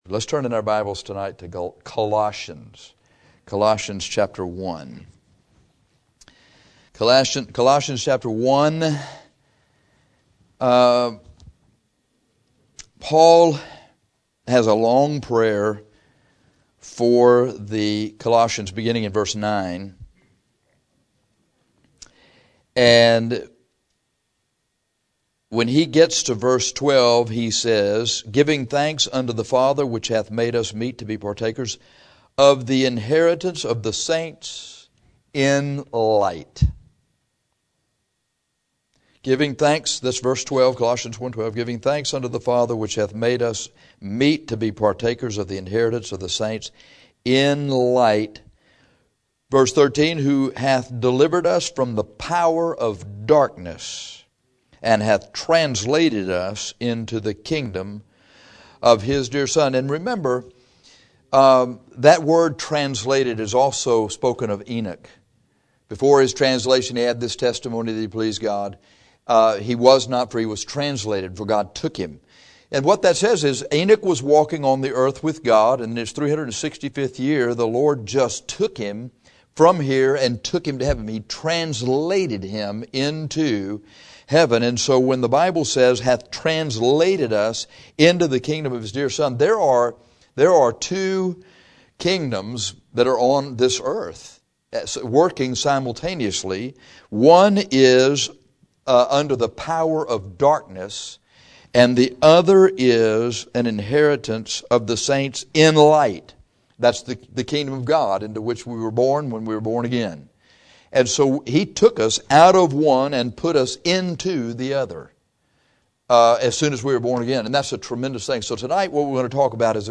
This sermon is about spiritual darkness and light. Before a man gets saved he walks in darkness.